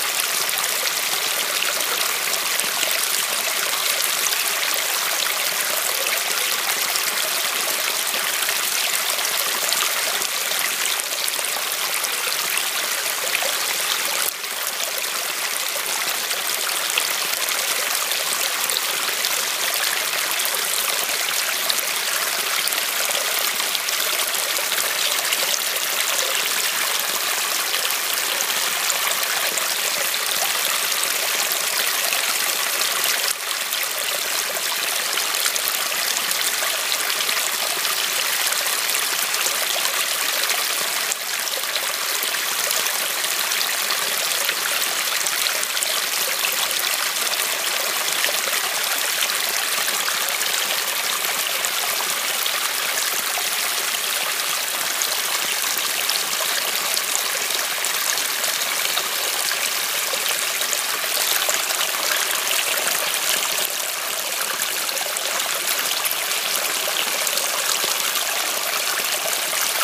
auWaterfallEmission.wav